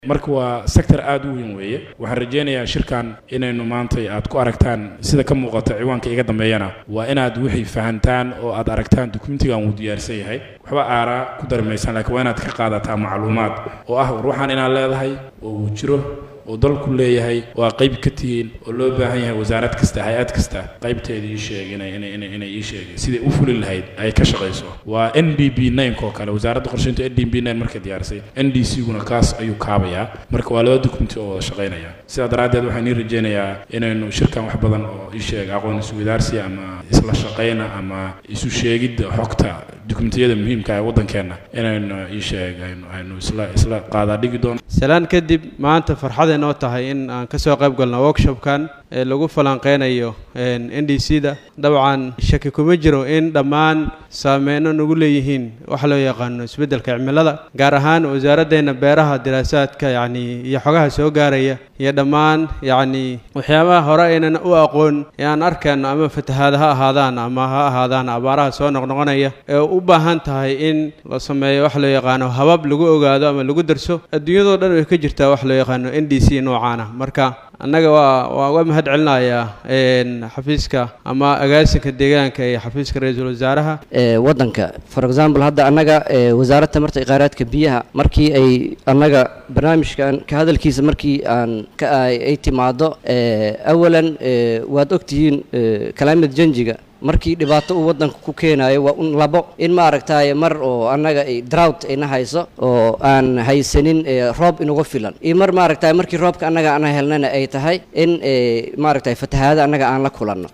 Kulan looga hadlayey isbadalka cimilada iyo saamaynta ay ku yeelan karto dalka Soomaaliya ayaa lagu qabtay Magaalada Muqdisho. Kulankaan ayaa waxaa soo qabanqaabiyay agaasinka deegaanka iyo isbadalka Cimilada ee xafiiska ra’isulwasaaraha Soomaaliya waxaana ka qayb galay wasaarada iyo waaxyo kale oo dowlada Fedraalka Soomaaliya ka tirsan. Masuuliyiintii goobta ka hadashay ayaa hoosta kawada xariiq muhiimada ay leedaha waxka qabashada isbadalada cimilada.